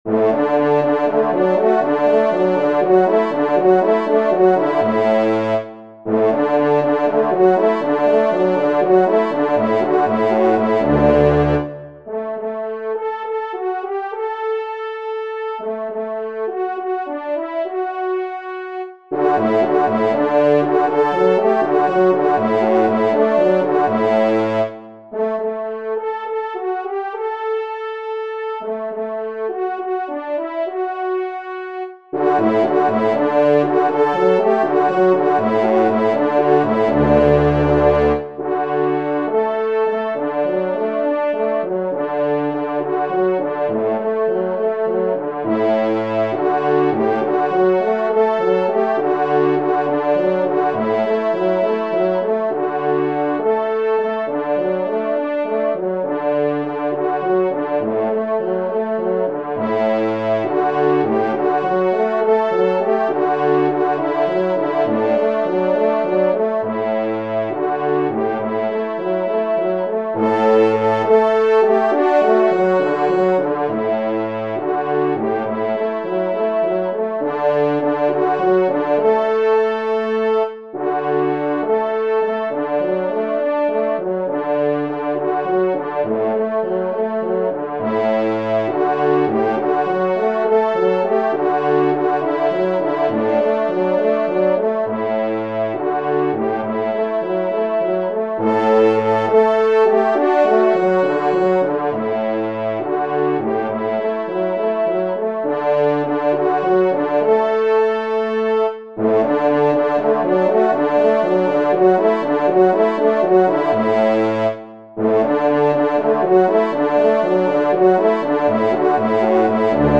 Genre :  Divertissement pour Trompes ou Cors
Pupitre 4°  Cor